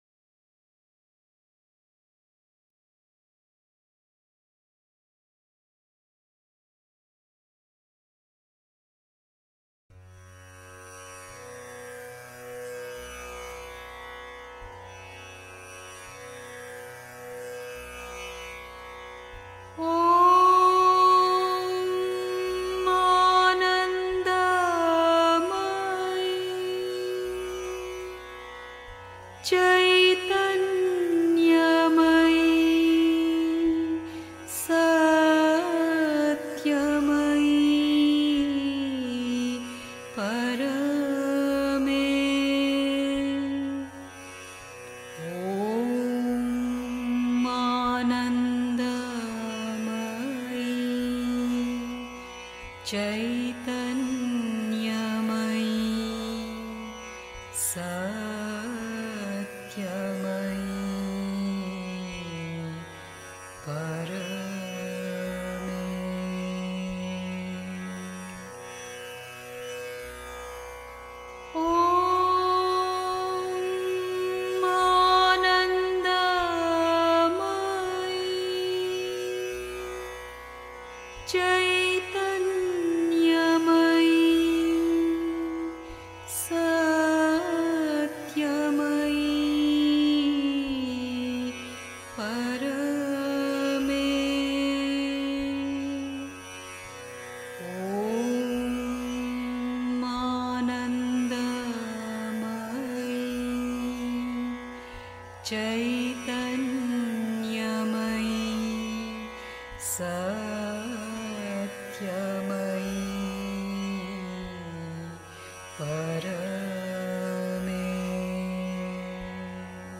1. Einstimmung mit Musik. 2. Beleidigungen: Bleibe unbewegt (Die Mutter, The Sunlit Path) 3. Zwölf Minuten Stille.